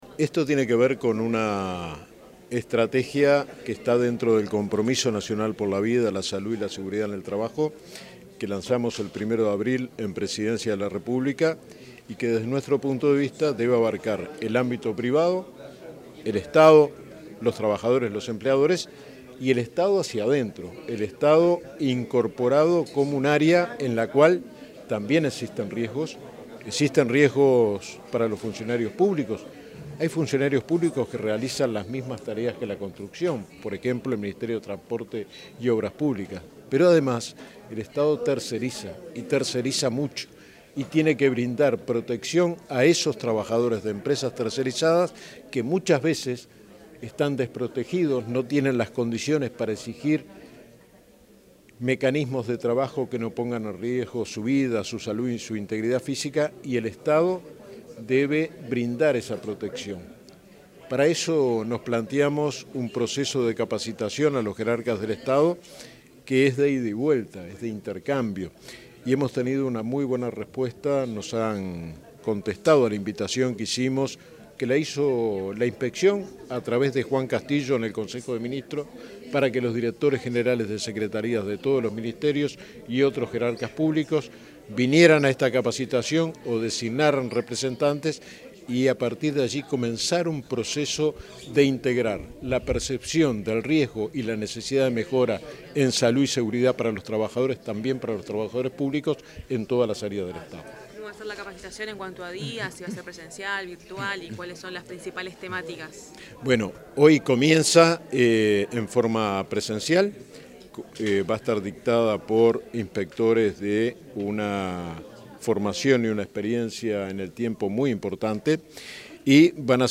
Declaraciones del inspector general del Trabajo y de la Seguridad Social, Luis Puig
El inspector general del Trabajo y de la Seguridad Social, Luis Puig, realizó declaraciones, tras la apertura de una jornada de capacitación sobre